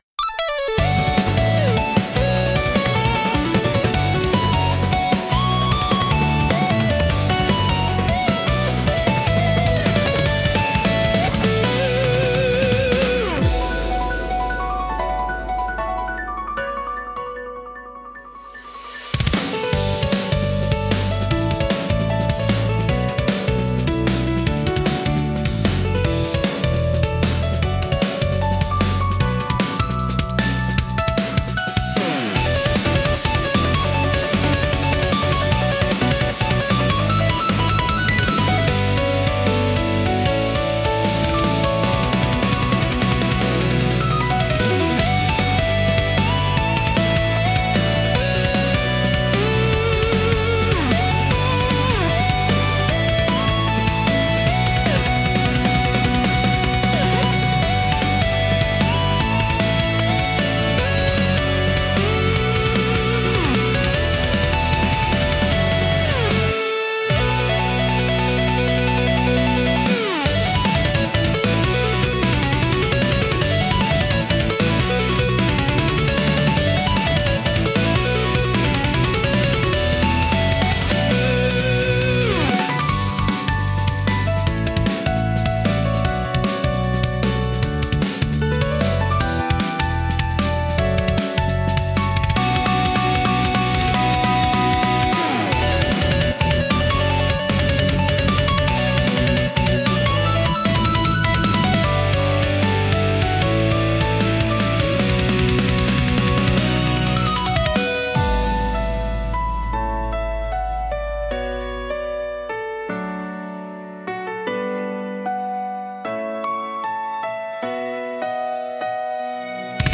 BGMセミオーダーシステムオリジナルのシューティングは、アップテンポでスタイリッシュな曲調が特徴のジャンルです。
速いビートとエネルギッシュなサウンドがスピード感や緊張感を強調し、プレイヤーの集中力を高めます。
テンポの速いリズムと洗練されたサウンドで、スリルと爽快感を演出し、没入感を促進します。